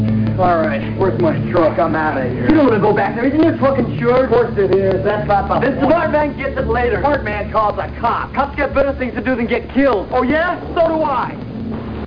truck.mp3